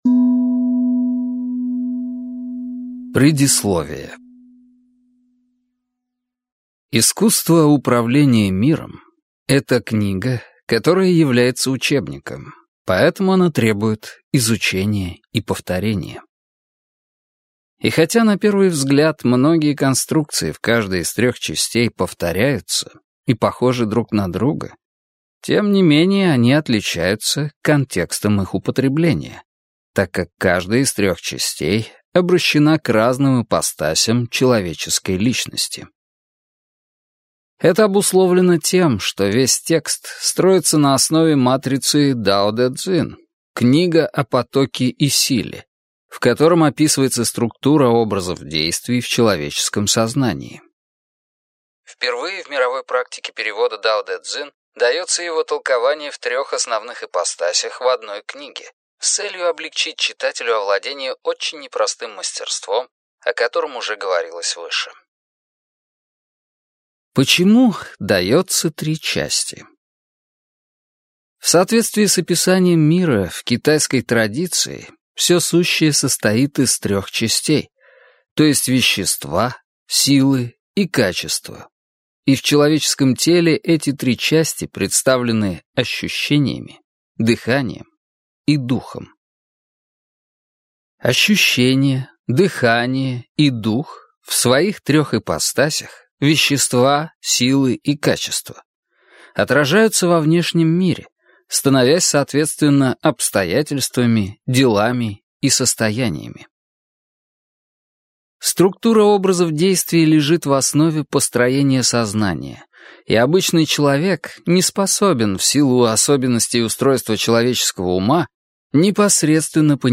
Аудиокнига Искусство управления миром | Библиотека аудиокниг